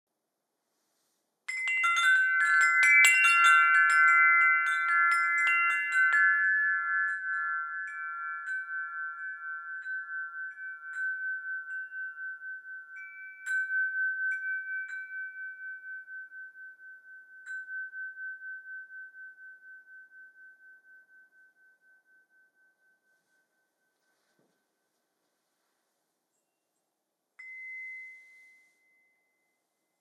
These chimes are characterized by a particularly good resonance and long reverberation. They are tuned on the base A4/a' 432 Hz. Enjoy the clear and cheerful sound.
Their magical sound fills the whole environment and invites you to dream.